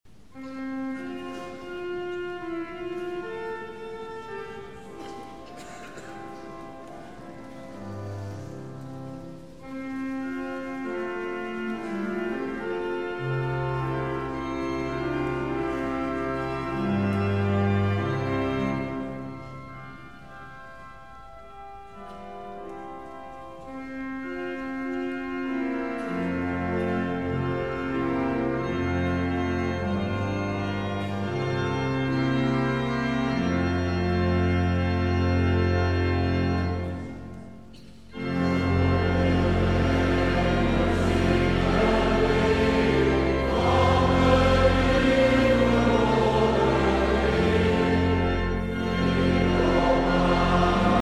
Orgel
Piano
Bariton
Mezzosopraan